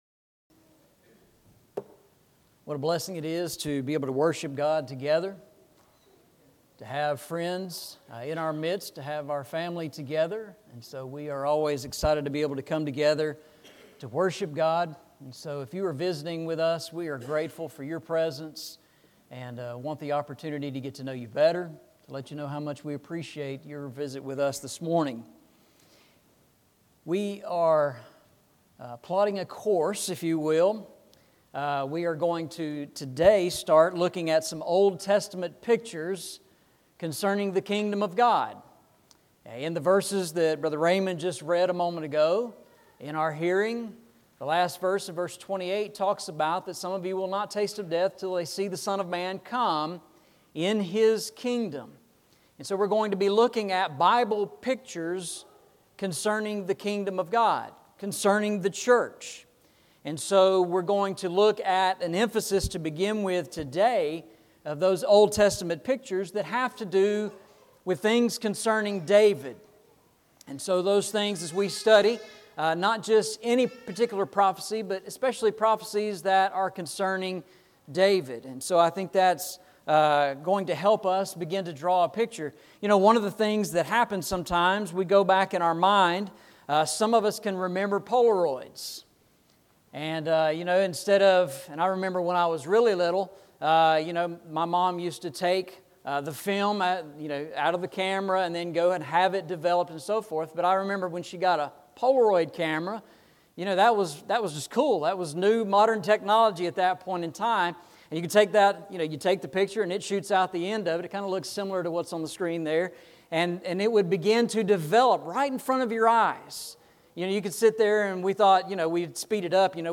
Eastside Sermons Passage: Matthew 16:24-28 Service Type: Sunday Morning « A Little Child Shall Lead Them